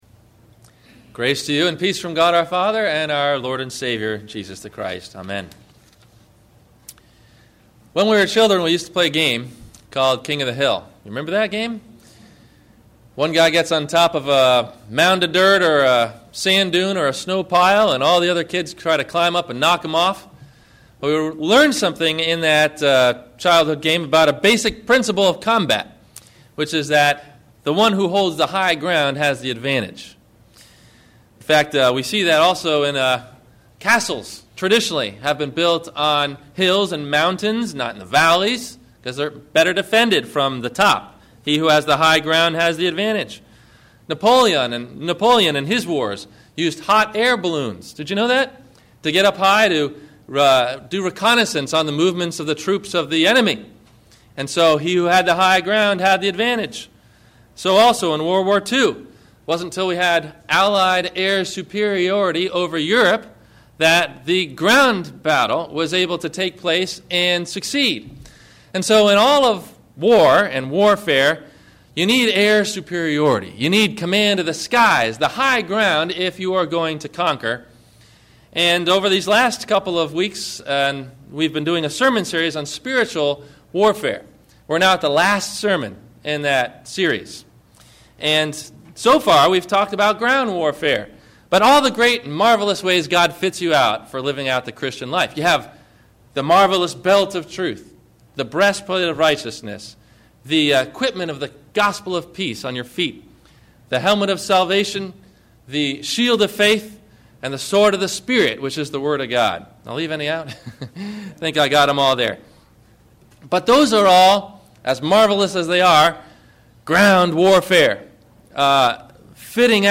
AIR SUPERIORITY – (Prayer for America,- the election) – Sermon – November 02 2008